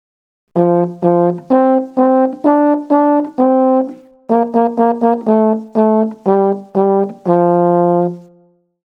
groep6_les1-5-2_blaasinstrumenten8_tuba.mp3